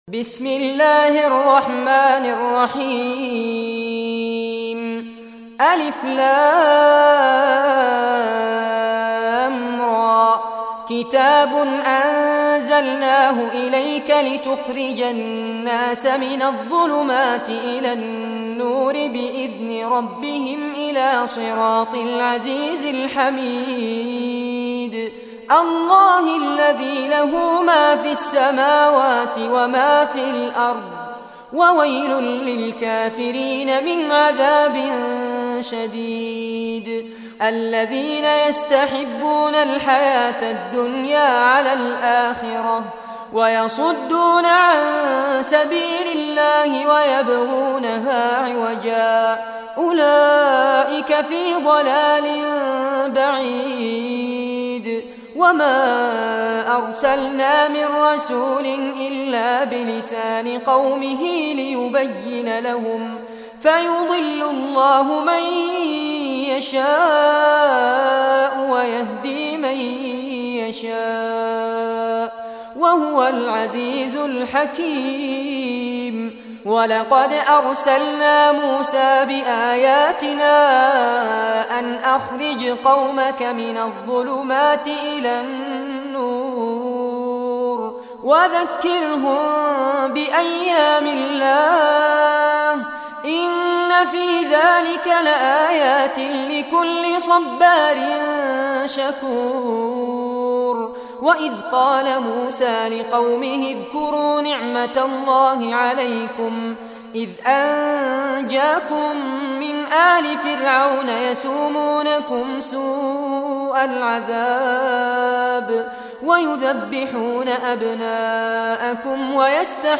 Surah Ibrahim Recitation by Sheikh Fares Abbad
Surah Ibrahim, listen or play online mp3 tilawat / recitation in Arabic in the beautiful voice of Sheikh Fares Abbad.